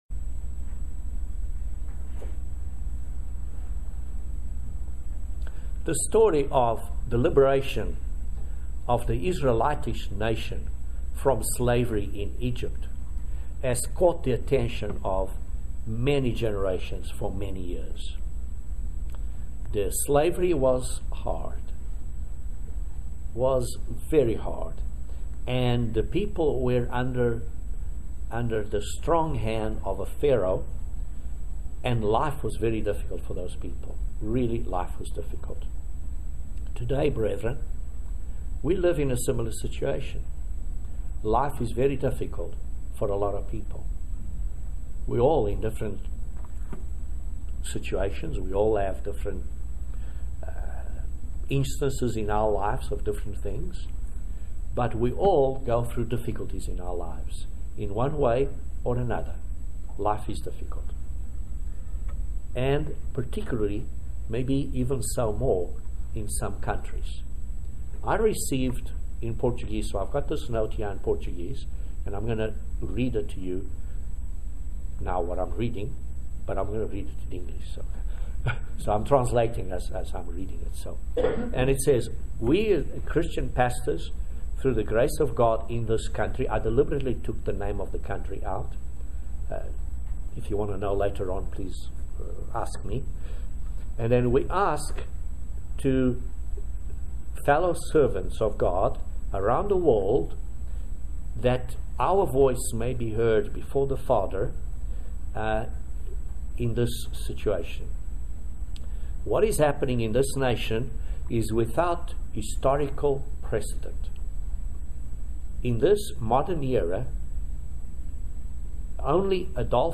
Excellent sermon on the passover and why we celebrate it on the 14th instead of another day. Join us for this eye opening sermon that will answer lots of your questions about the passover.